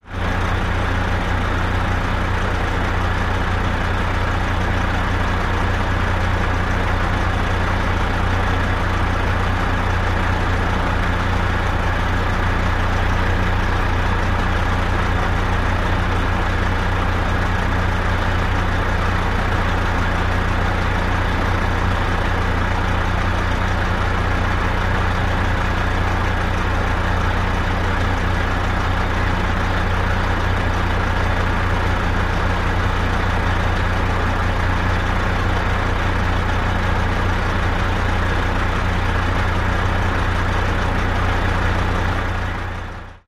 tr_dieseltruck_idle_02_hpx
Diesel truck idles. Vehicles, Truck Idle, Truck Engine, Motor